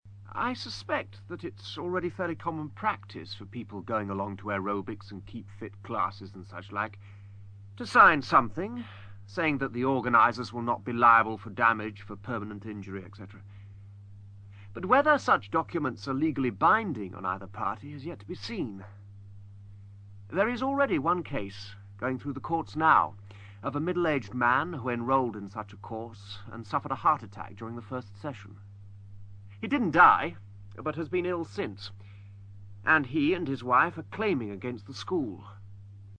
ACTIVITY 185: You are going to hear people from different professions talking about the boom in the 'Keep-Fit' industry.